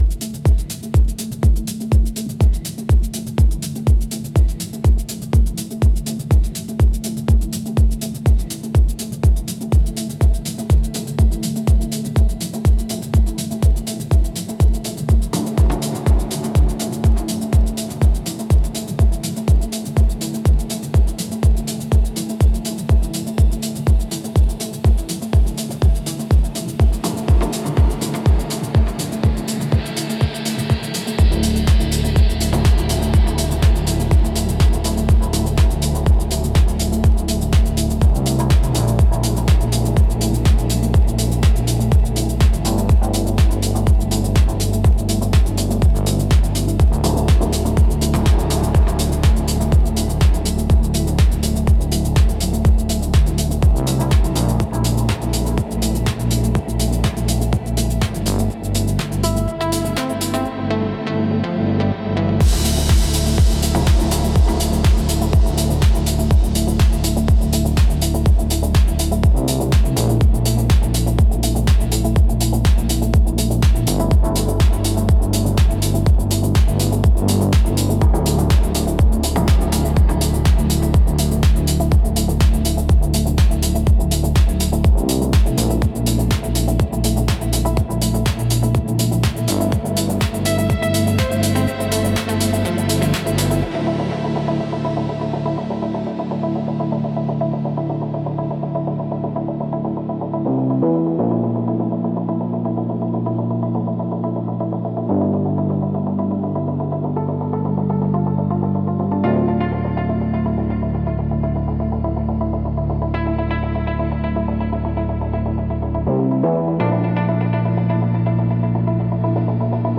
Instrumental - Pressure of a Memory- 5.15